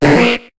Cri de Ramoloss dans Pokémon HOME.